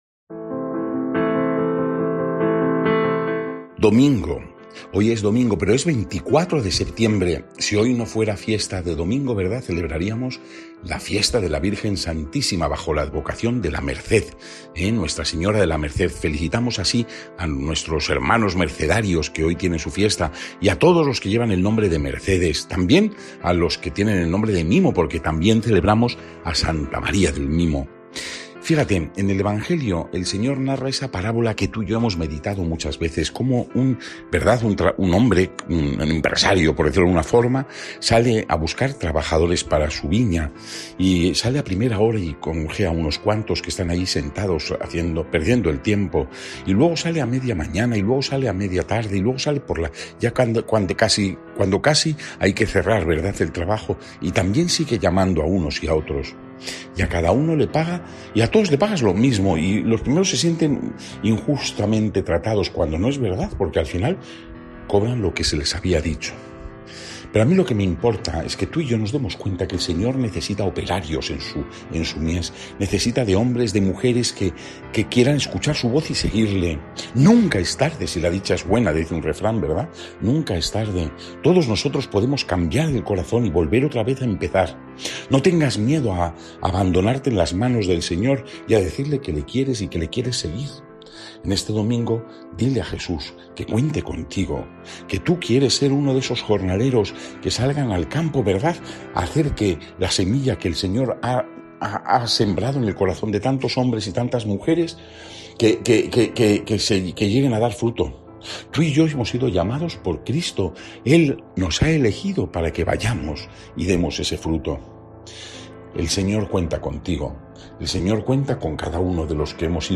Evangelio según san Mateo (20, 1-16) y comentario